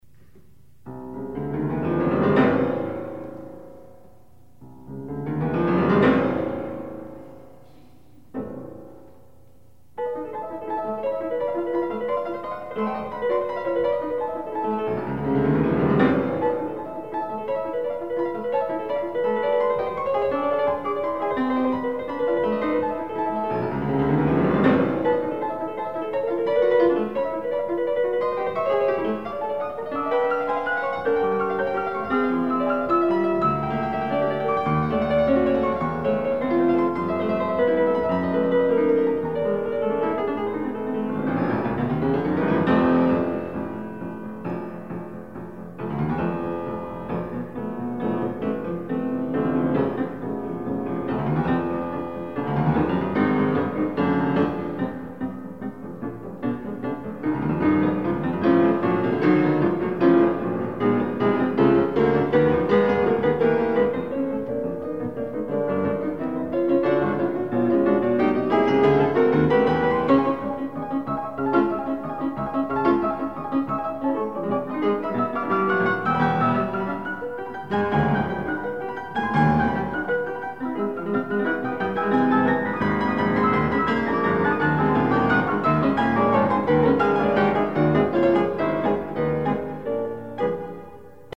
Additional Date(s)Recorded September 12, 1977 in the Ed Landreth Hall, Texas Christian University, Fort Worth, Texas
SubjectEtudes
Suites (Piano)
Sonatas (Piano)
Short audio samples from performance